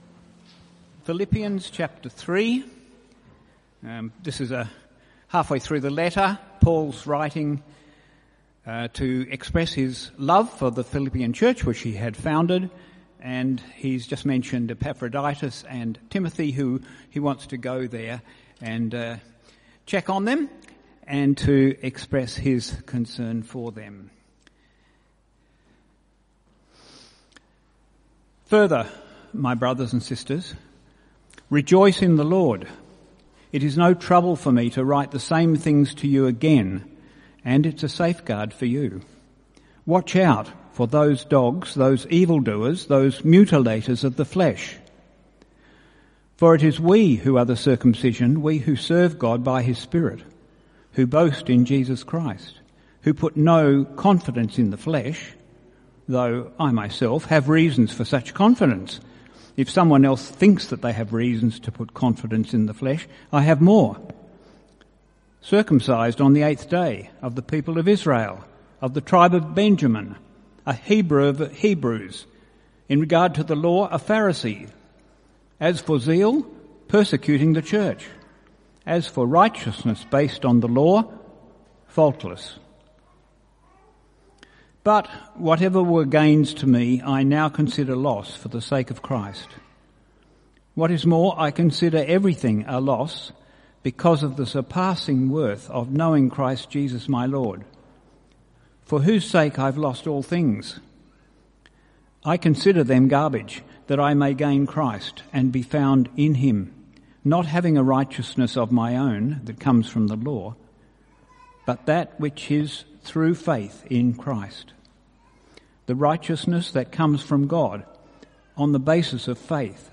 Type: Sermons CBC Service: 6 August 2023 (9:30)